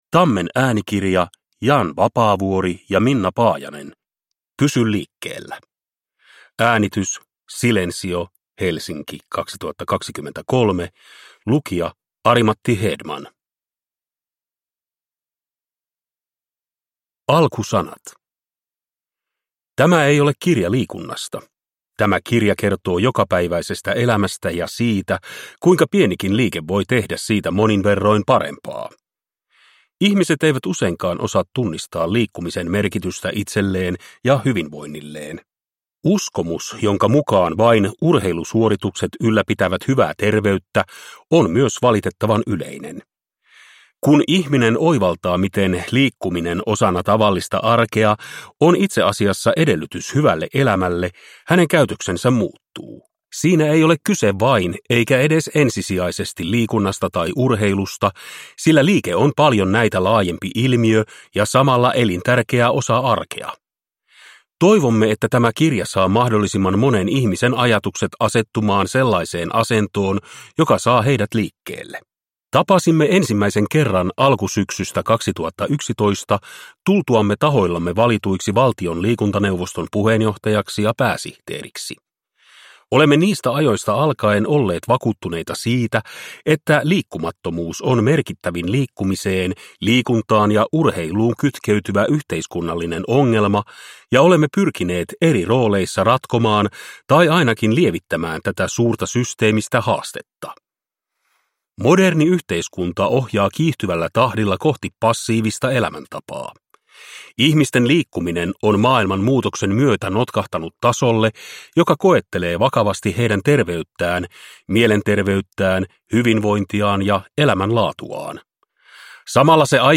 Pysy liikkeellä (ljudbok) av Jan Vapaavuori